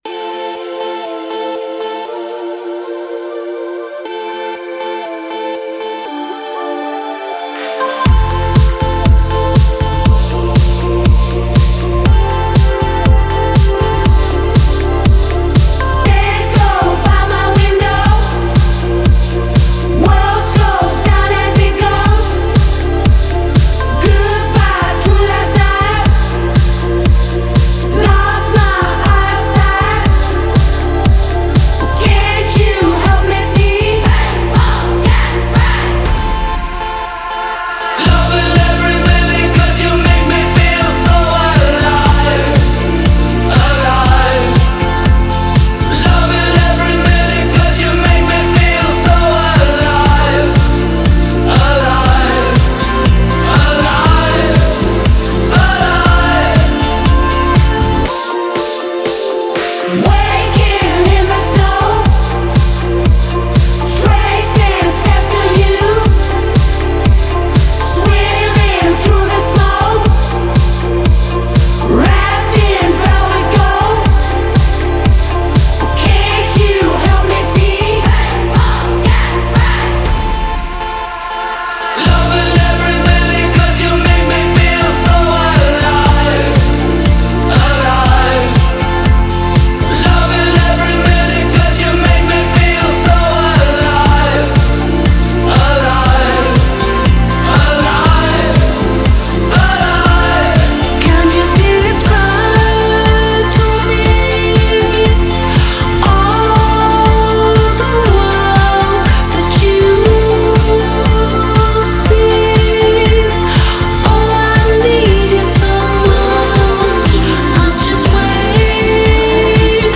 Genre: Electropop